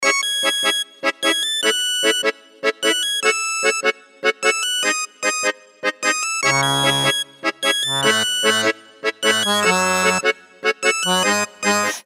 Рингтоны без слов
Аккордеон , Мелодичные
Инструментальные